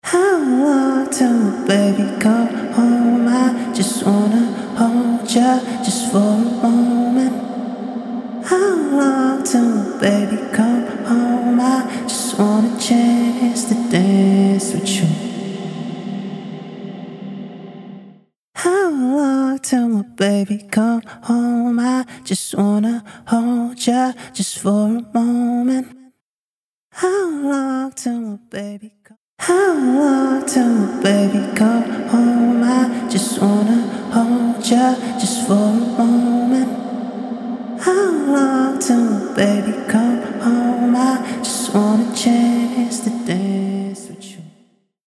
Massive Otherworldly Reverb
Blackhole | Vocals | Preset: Cathedral
Blackhole-Eventide-Vocals-Cathedral.mp3